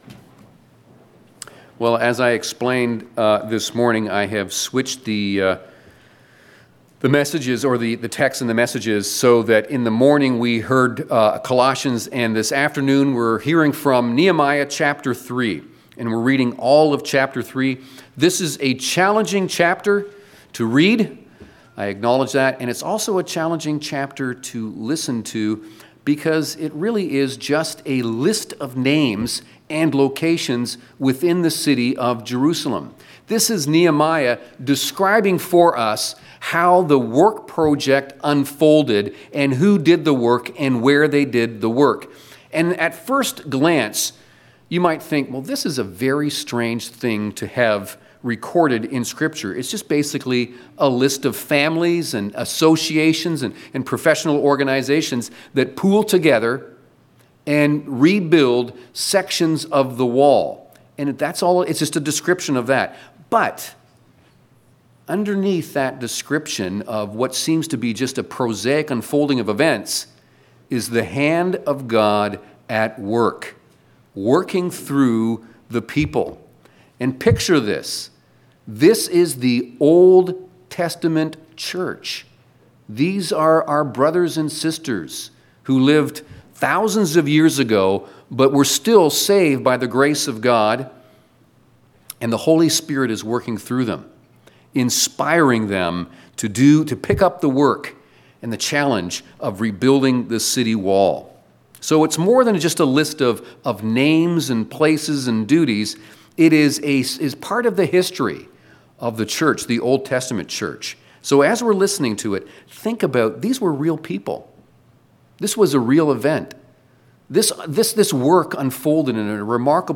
5.sermon_The-Godly-Ruler-Guards-and-Defends-His-People-.mp3